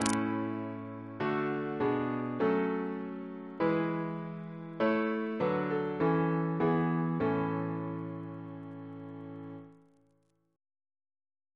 Single chant in A Composer: Richard Woodward, Jr. (1744-1777), Organist of Christ Church Cathedral, Dublin Reference psalters: H1982: S17 S43; OCB: 241; PP/SNCB: 52